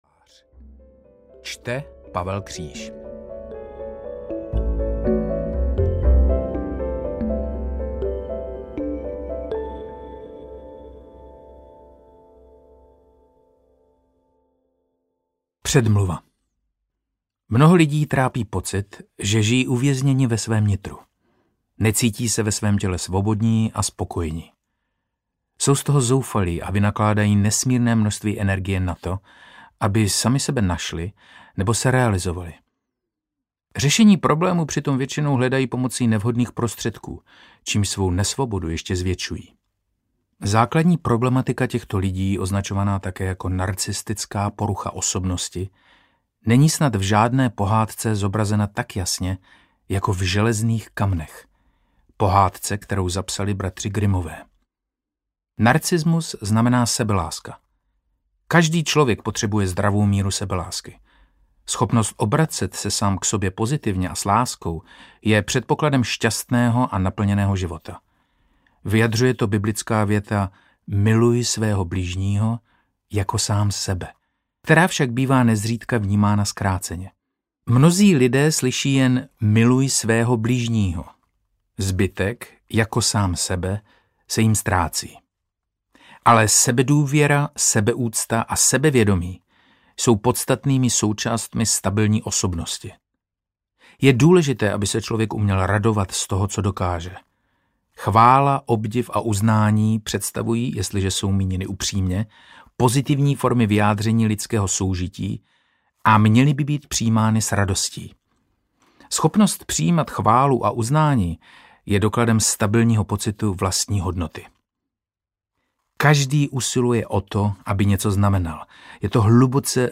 Narcismus - vnitřní žalář audiokniha
Ukázka z knihy
• InterpretPavel Kříž